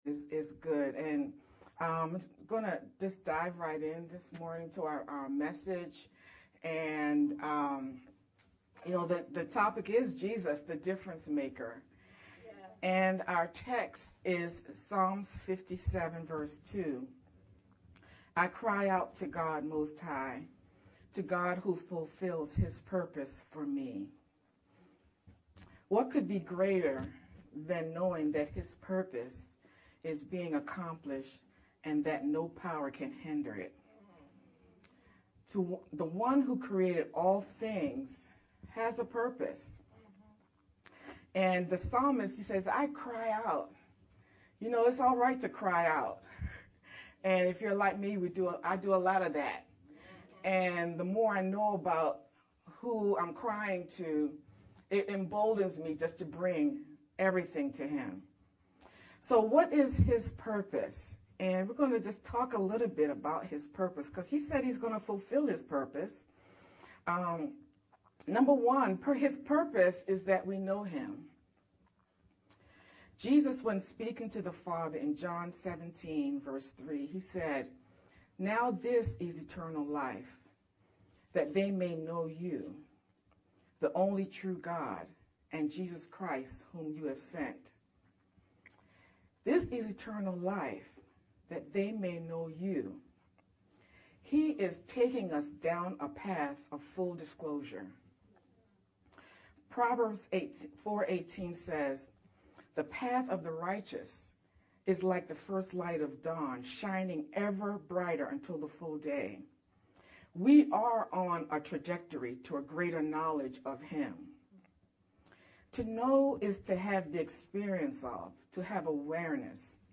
VBCC-30th-Sermon-only-MP3-CD.mp3